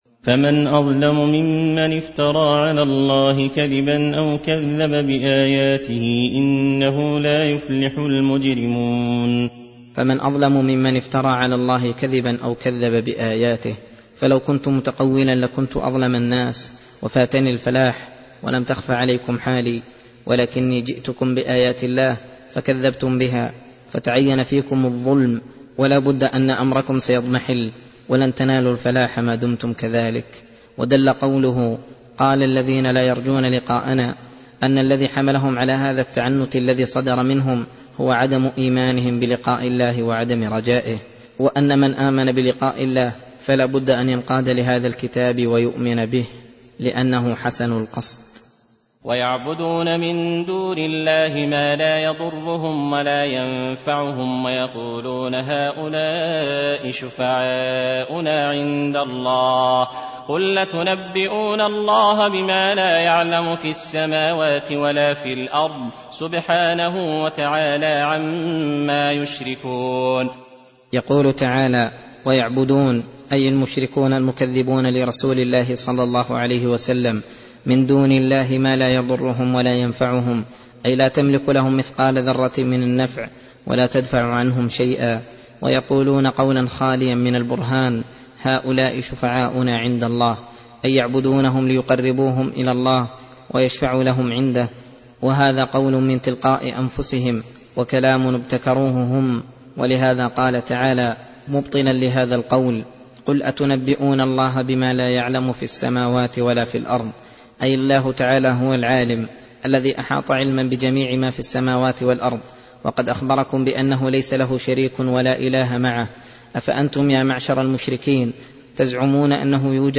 درس (26) : تفسير سورة يونس (17-33)